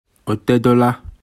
Captions English Audio pronunciation of Otedola